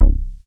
DEEP C2.wav